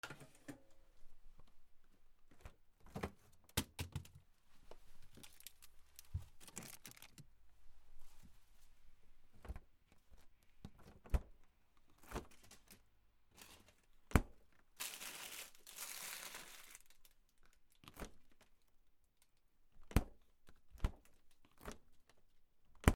冷凍庫